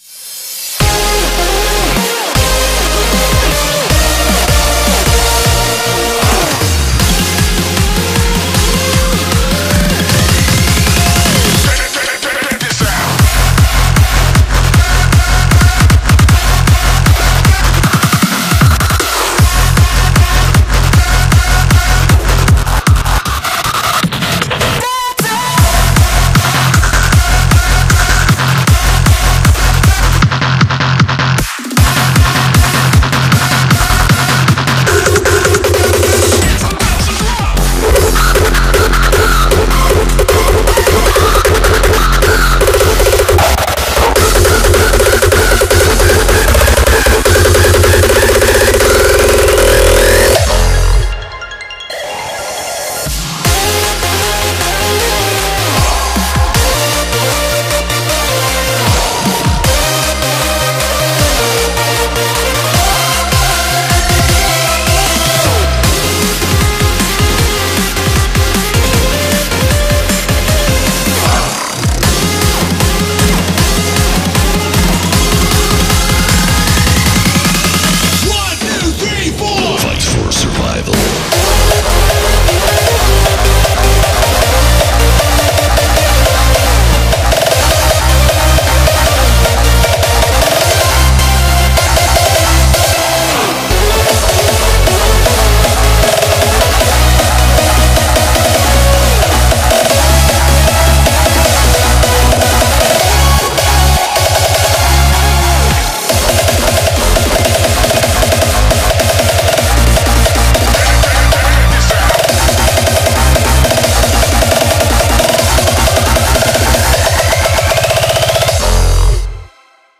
BPM155-310
Audio QualityPerfect (High Quality)
Comentarios[HARDSTYLE]